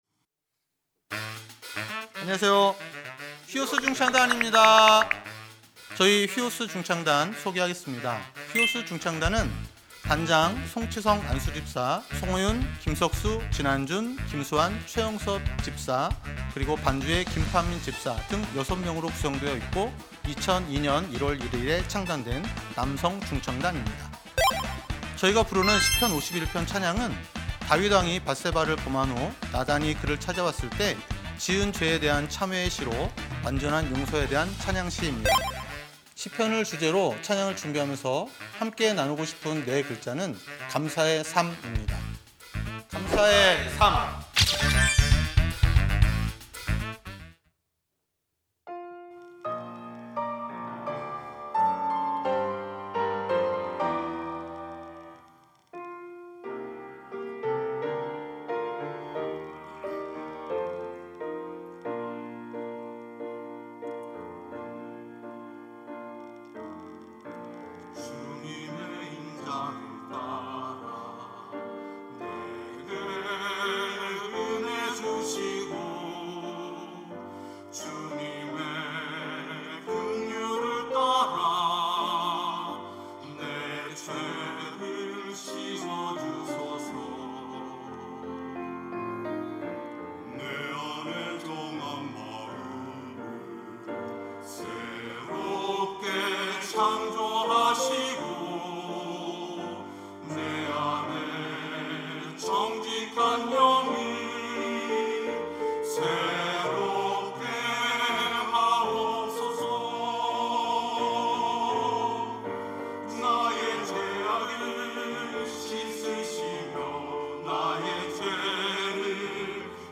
찬양대 휘오스